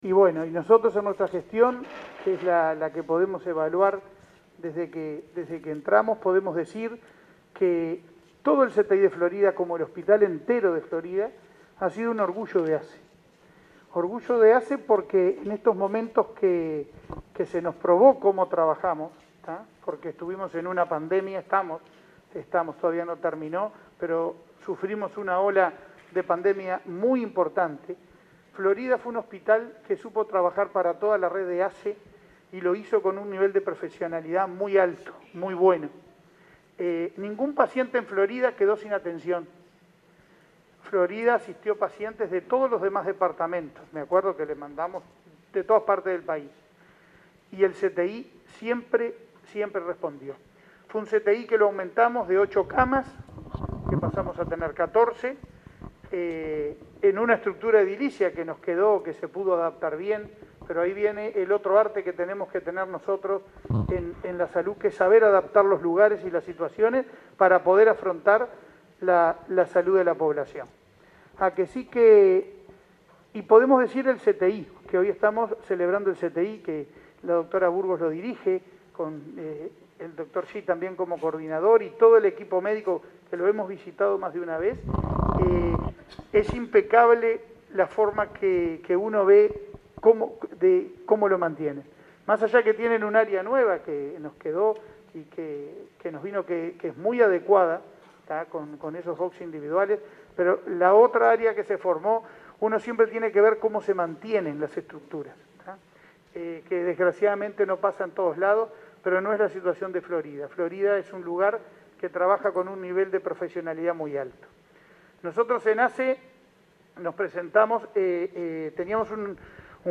Palabras del presidente de ASSE, Leonardo Cipriani
Palabras del presidente de ASSE, Leonardo Cipriani 20/08/2021 Compartir Facebook X Copiar enlace WhatsApp LinkedIn Este viernes 20, el presidente de ASSE, Leonardo Cipriani, participó de la conmemoración de los 25 años de la Unidad de Cuidados Intensivos del hospital de Florida. En esa oportunidad, adelantó que se trabaja en la remodelación de las áreas de pediatría y maternidad de ese nosocomio.